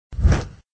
SPlateDown.ogg